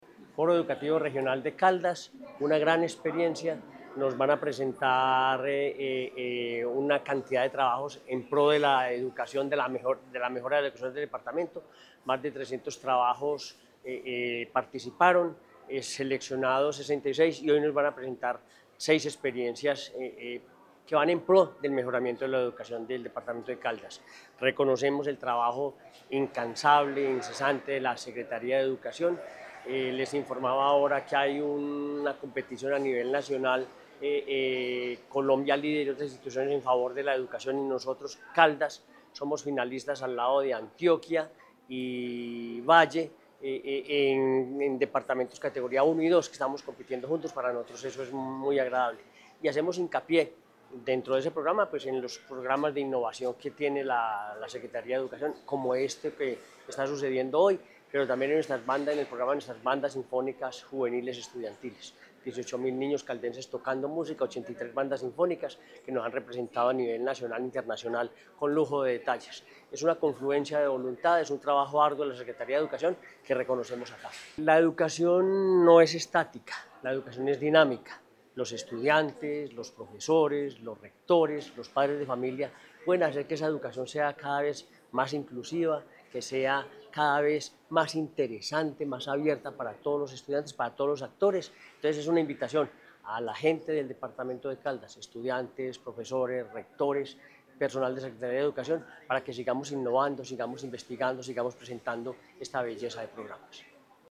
El gobernador de Caldas, Henry Gutiérrez Ángel, realizó la apertura de este encuentro y resaltó la importancia de la educación como elemento transformador de vidas, que permite que más familias mejoren sus condiciones y que más niños y jóvenes trabajen por un futuro más prometedor.
Henry Gutiérrez Ángel, gobernador de Caldas